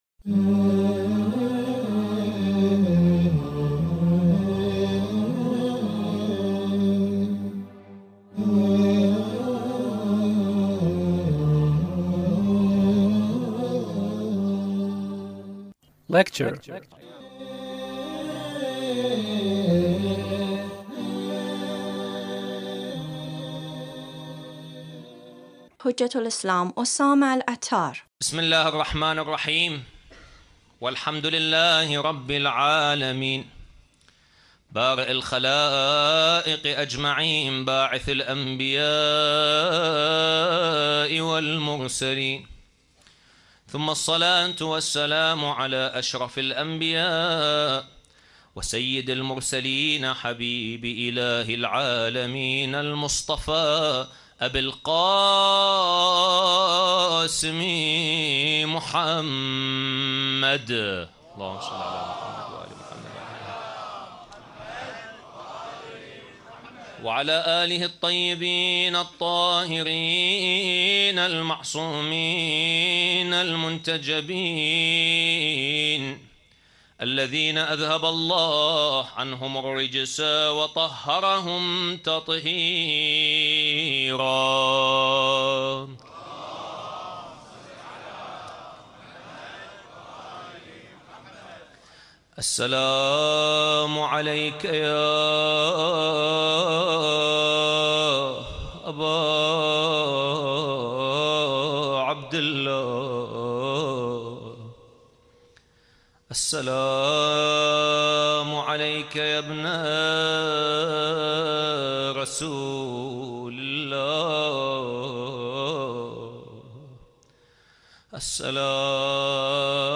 Lecture (25)